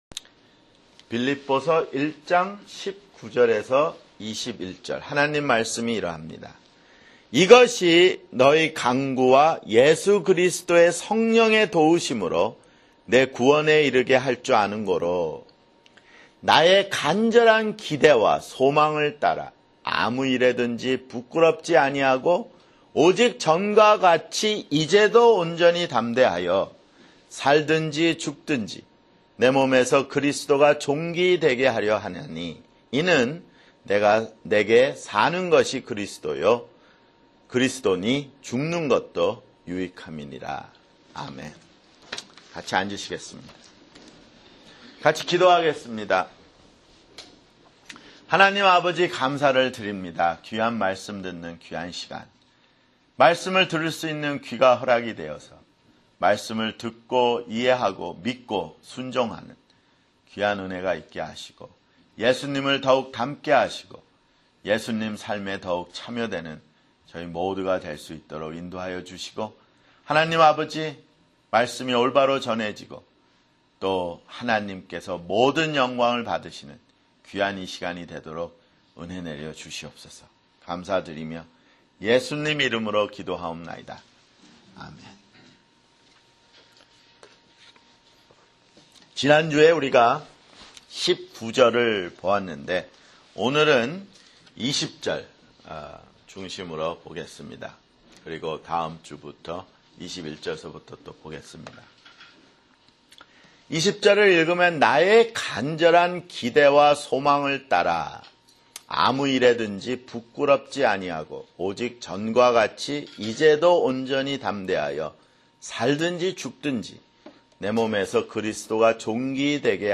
[주일설교] 빌립보서 (16)